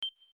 system_ok.mp3